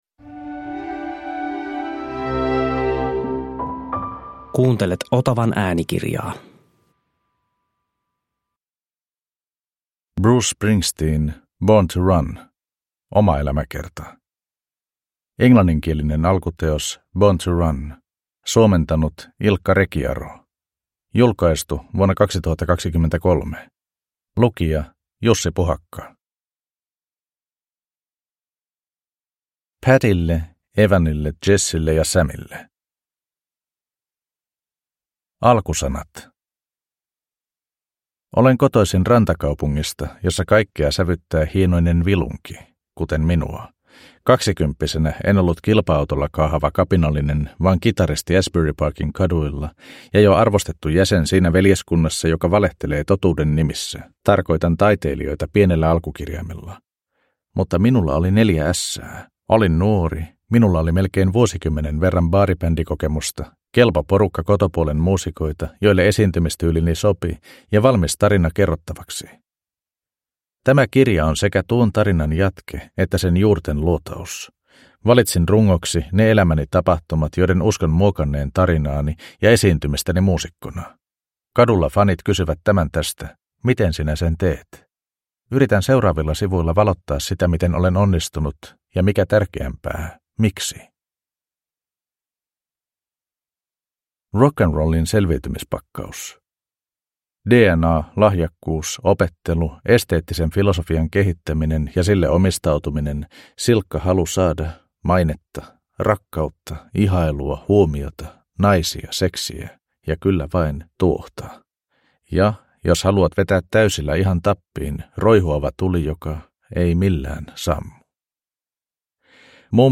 Born to Run – Ljudbok